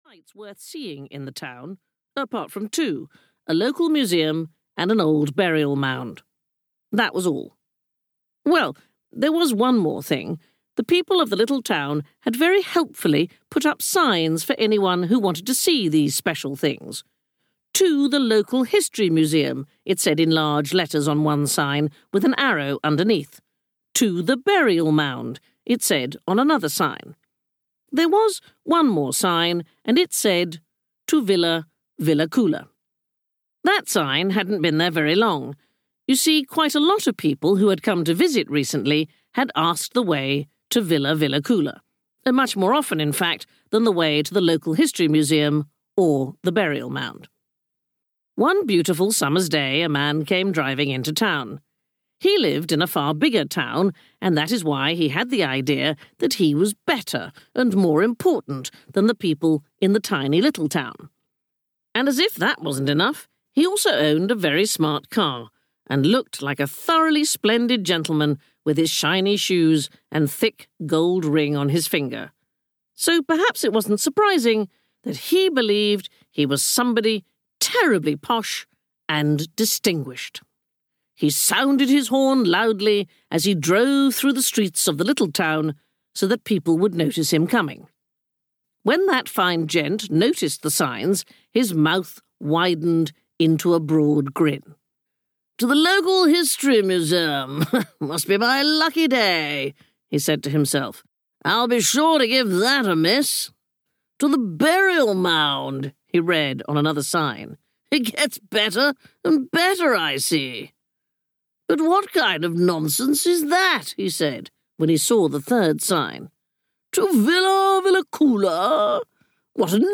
Pippi Longstocking in the South Seas (EN) audiokniha
Ukázka z knihy
• InterpretSandi Toksvig